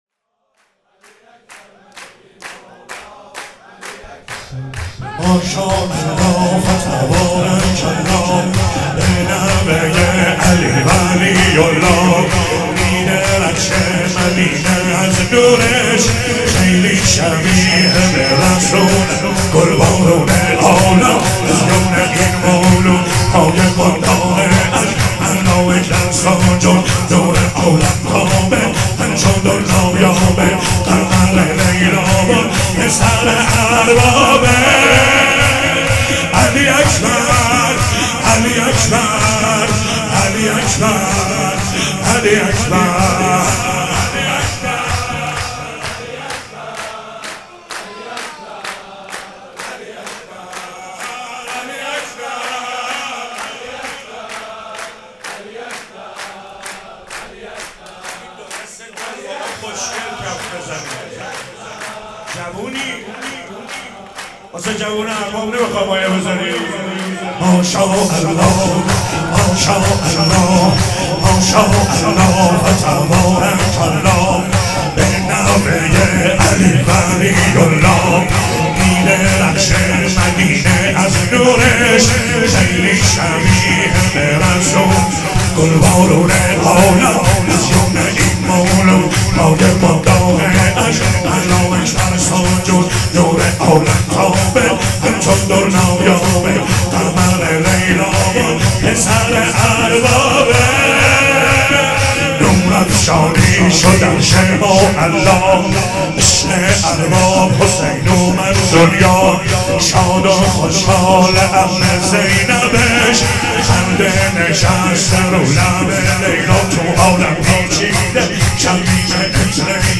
چهاراه شهید شیرودی حسینیه حضرت زینب (سلام الله علیها)
شور- ماشالله فتبارک به نوه علی ولی الله